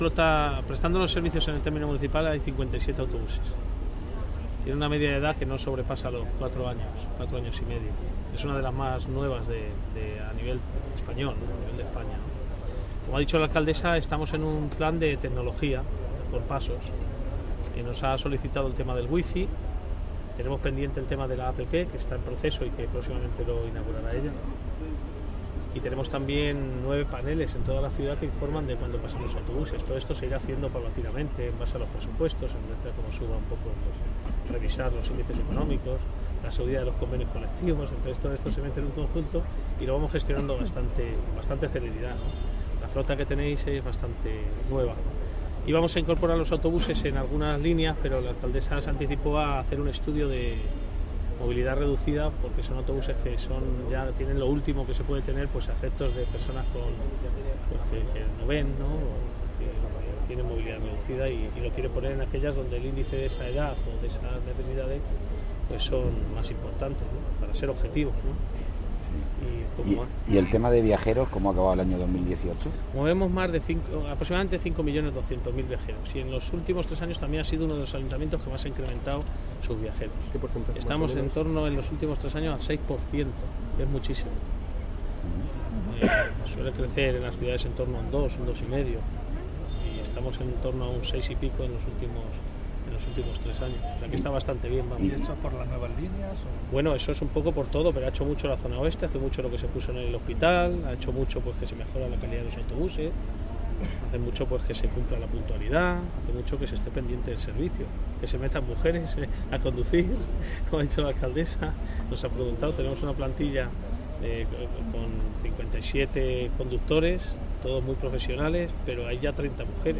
Audio: Declaraciones de la alcaldesa de Cartagena sobre los nuevos autobuses (MP3 - 3,98 MB)